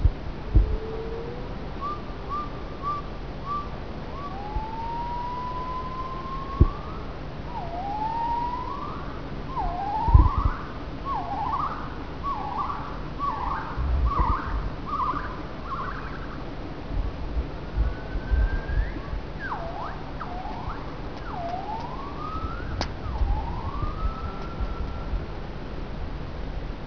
Fully developed male vocalisations consist of three different types of notes typically uttered in the following succession: one boom produced during inflation of throat sac, a series of short simple staccato notes ("aa"), and a series of highly frequency modulated notes (termed multi-modulated figure by Haimoff, 1984).
Great call with an acceleration-type climax, like H. moloch, of moderate speed, not becoming slower near end.
Twitter-like vocalisation at the end of great call.
Duet song bouts.
Press to start sound Duet song, Xujiaba, Ailao mountains, China, 27 Aug. 1990.